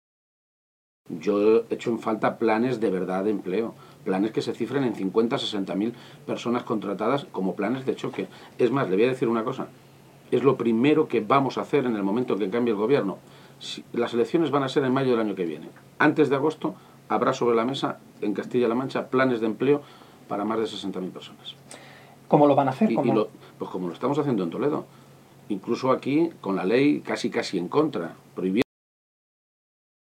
Cortes de audio de la rueda de prensa
Audio García-Page entrevista Ser 1